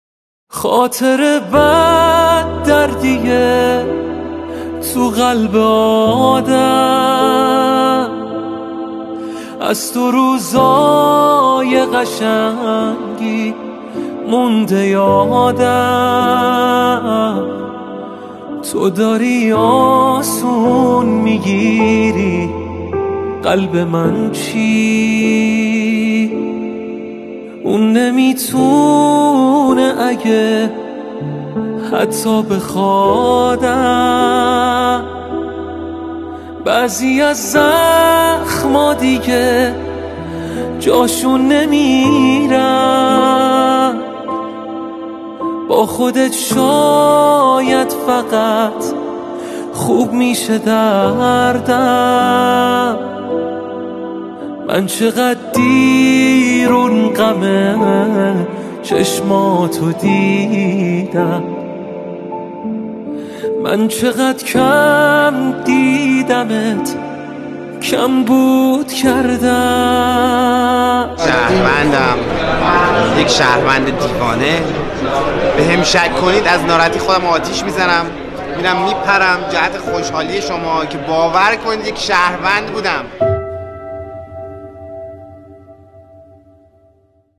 دانلود آهنگ پاپ